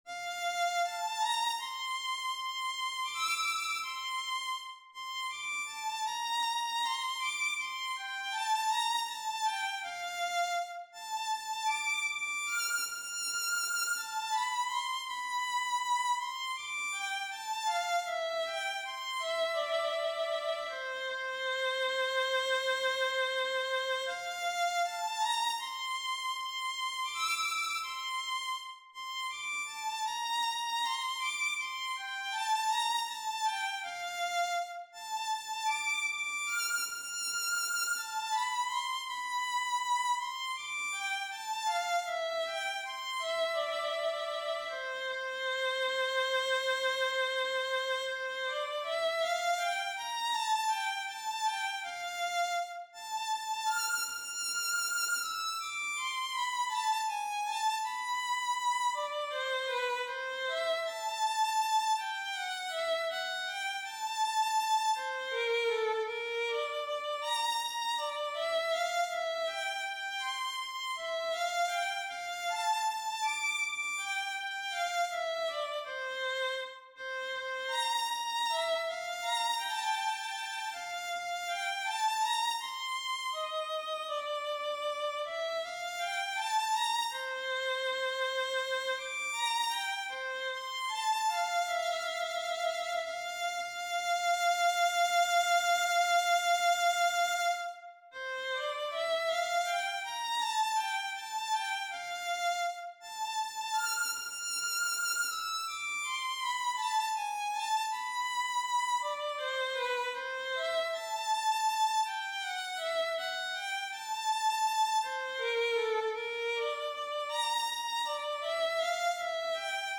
비발디 바이올린 소나타 B major rv33 > 바이올린 | 신나요 오케스트라